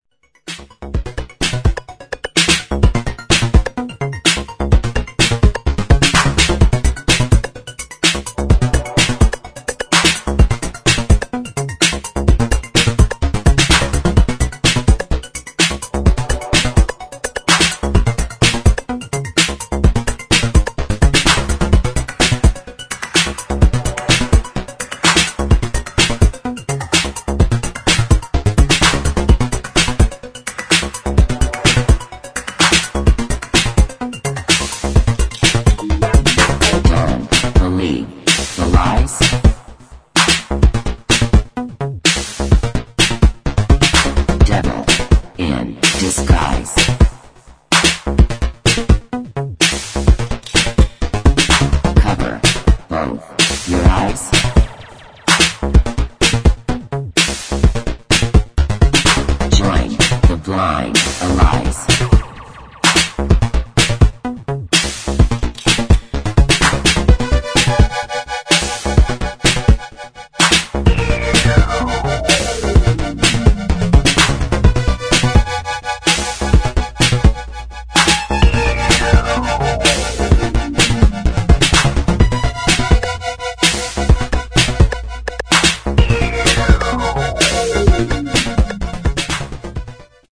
[ ELECTRO ]